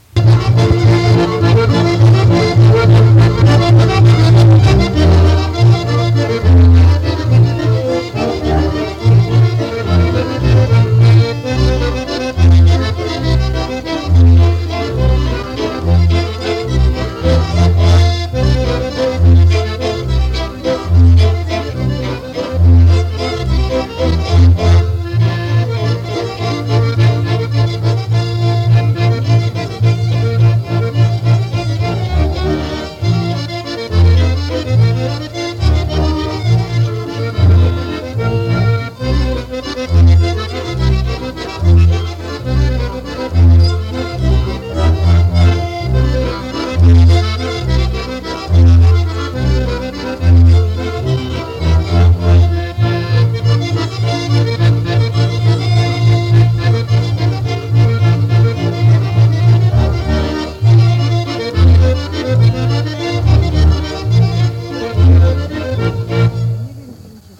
Instrumentalny 11 – Żeńska Kapela Ludowa Zagłębianki
Nagranie archiwalne